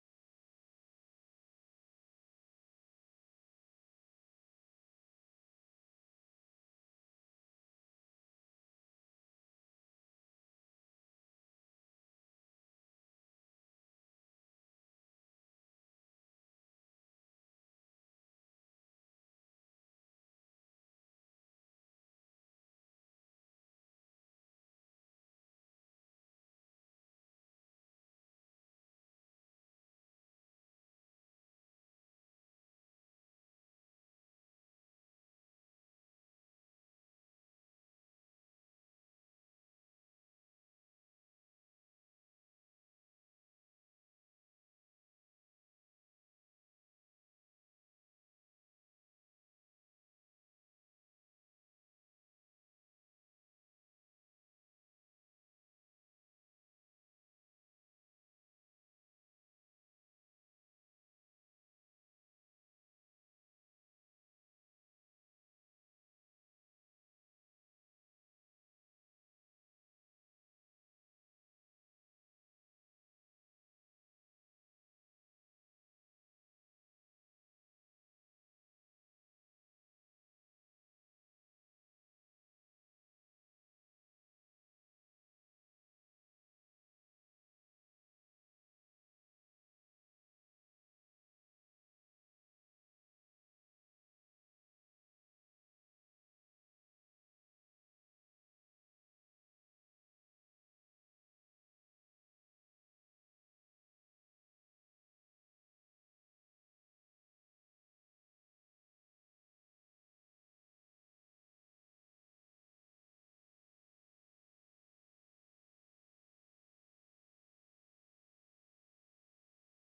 No dia 17/06, às 15:00 , o LamparinaCast, o podcast mais humano do Brasil, apresenta um episódio especial AO VIVO com Lúcia Helena Galvão!